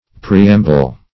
Preamble \Pre"am`ble\, v. t. & i.